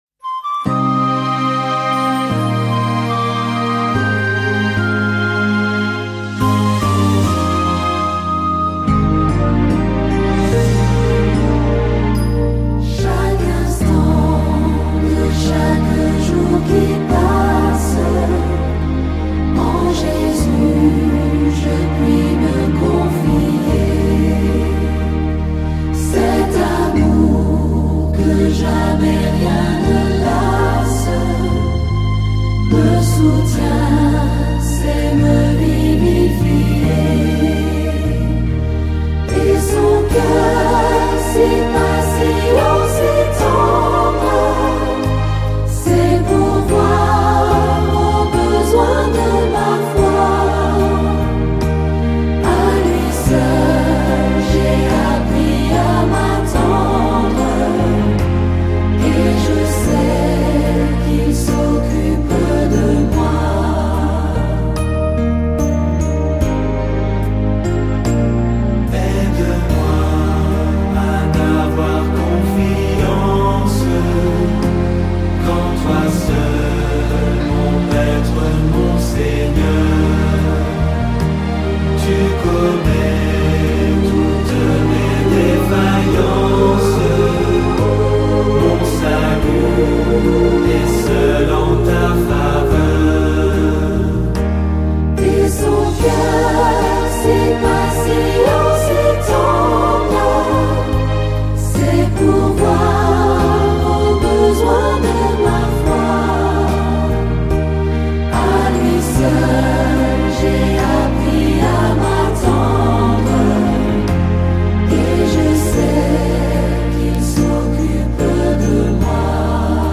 Cantique : « Chaque instant »